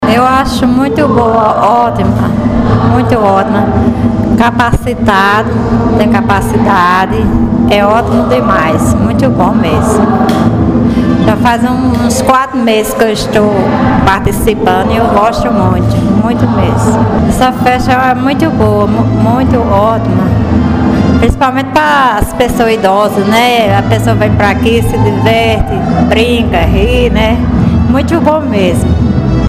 Fala da participante do evento